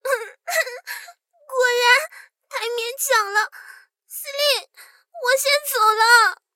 SU-76被击毁语音.OGG